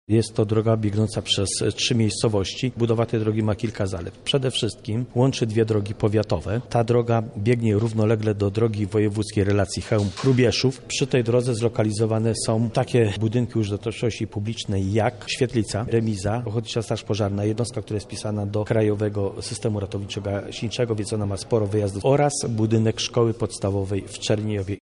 Umowy podpisano w ramach Narodowego Programu Przebudowy Dróg Lokalnych realizowanego przez Ministerstwo Administracji i Cyfryzacji. O korzyściach płynących z przebudowy drogi w gminie Kamień mówi Roman Kandziora, wójt gminy.